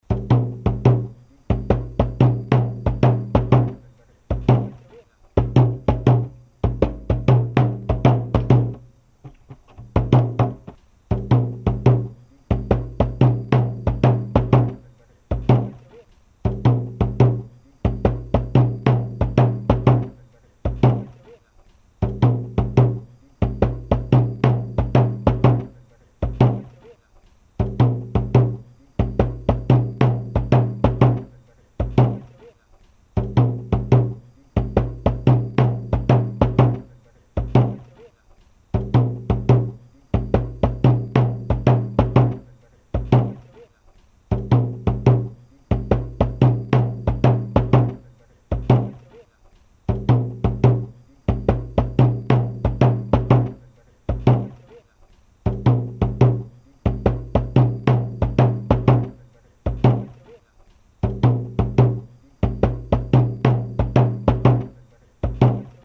The ompeh drum is an open single-headed conical drum made from one single piece of wood.
This drum has a clear sound and plays ostinato figures and cross rhythms. The ompeh is played alternately with a wooden drumstick and the palms and accompanies traditional dances such as the ompeh dance of the Efutu.
ompeh-dance2.mp3